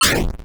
soundeffect.m4a